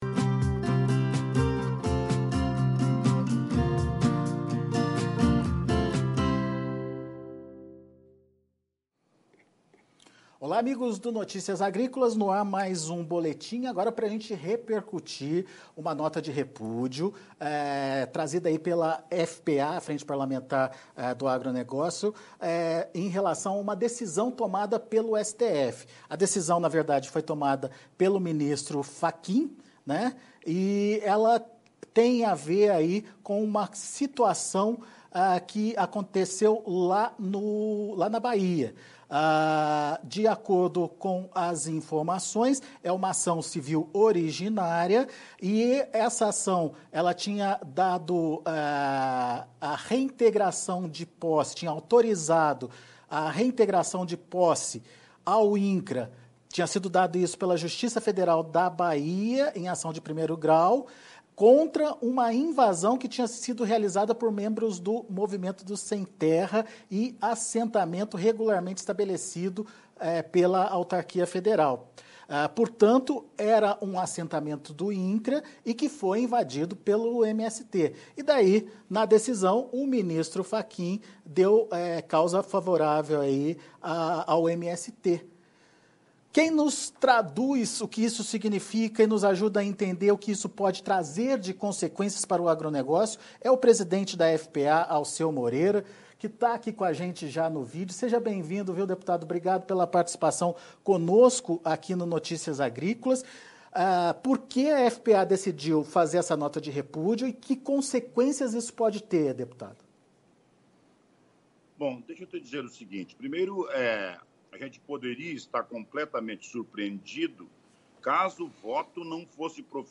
Entrevista com Alceu Moreira - Presidente da FPA sobre a FPA é contra reintegração de posse a favor do MST